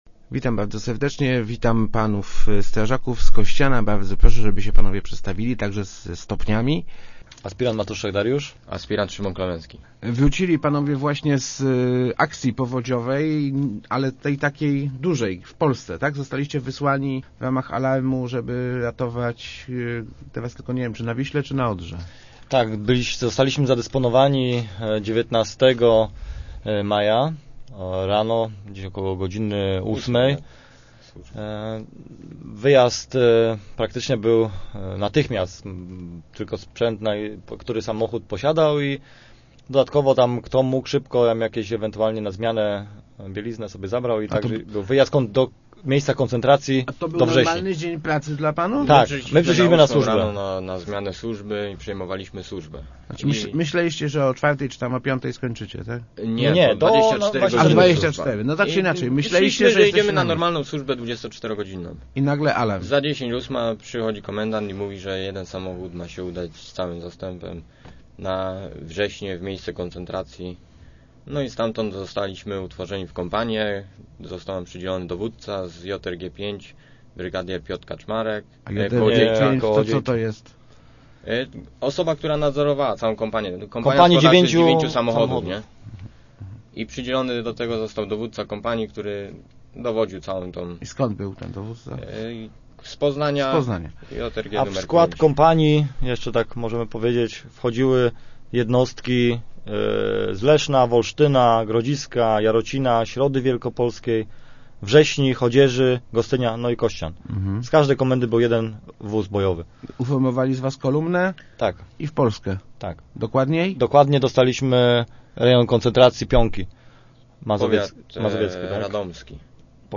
W Rozmowach Elki opowiadali, jak wyglądała ich praca. Nie była łatwa – czterdziestokilogramowe worki z piaskiem musieli nosić kilkaset metrów, żeby wzmacniać wiślany wał. Udało się wygrać z żywiołem, choć woda podeszła do samego szczytu wału.